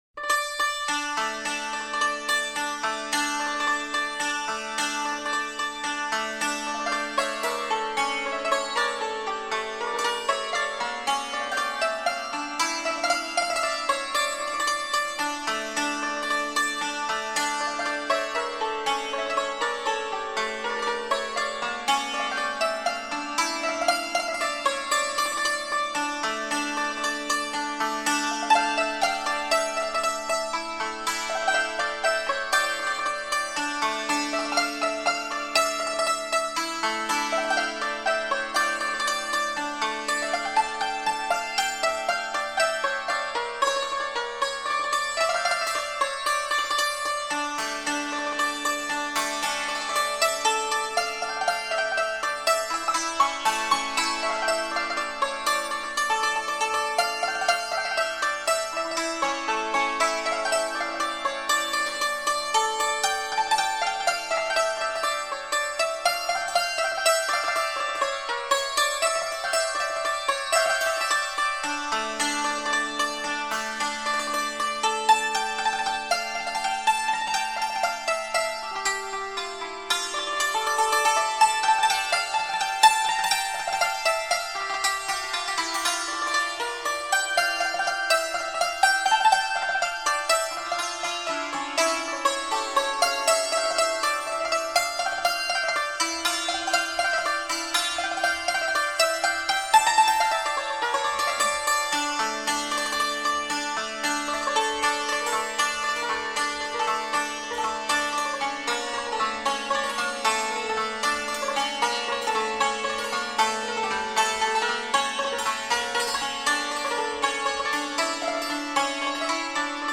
مجموعه ای از مناجات ها و اشعار بهائی (سنتّی)